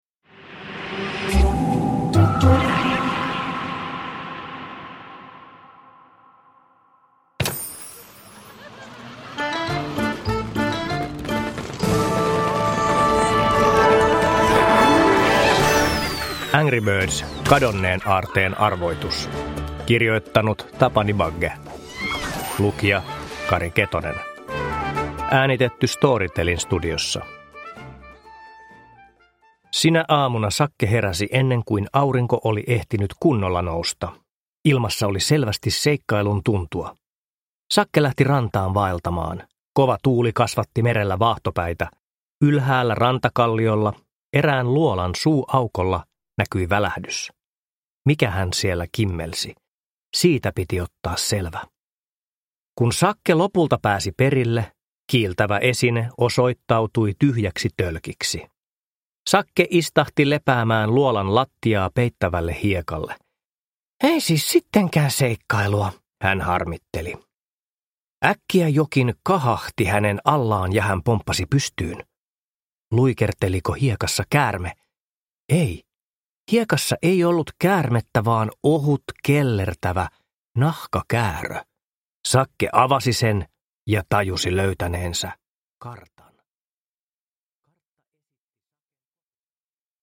Angry Birds: Kadonneen aarteen arvoitus – Ljudbok – Laddas ner